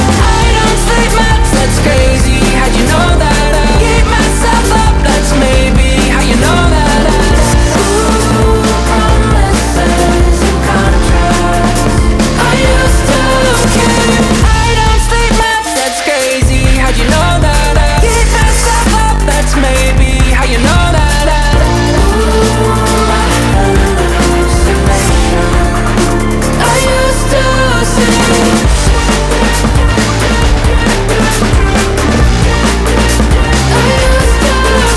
Alternative
Жанр: Альтернатива